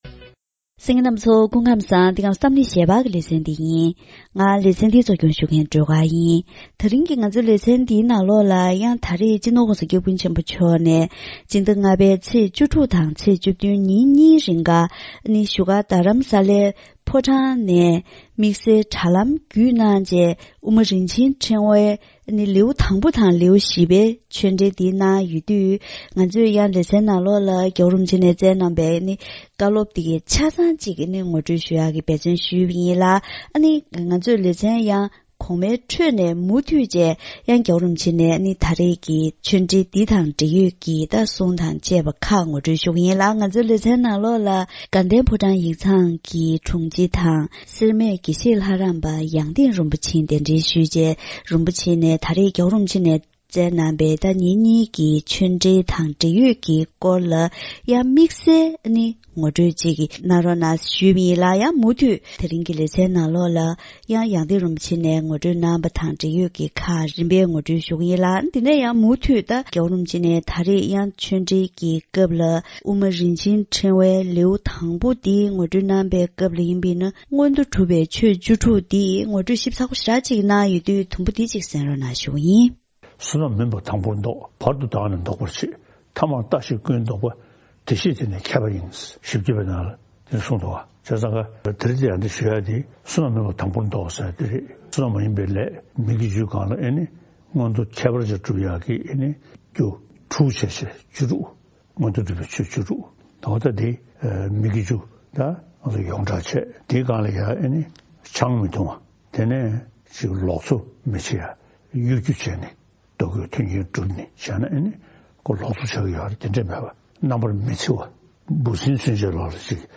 སྤྱི་ནོར་༧གོང་ས་སྐྱབས་མགོན་ཆེན་པོ་མཆོག་ནས་དྲ་ལམ་བརྒྱུད་དབུ་མ་རིན་ཆེན་ཕྲེང་བའི་བཀའ་ཆོས་གནང་པའི་སྐབས།